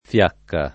fiacca [ f L# kka ] s. f.